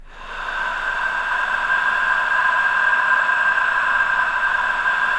I-NOISEBED.wav